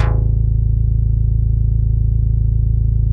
MICROFUNK A1.wav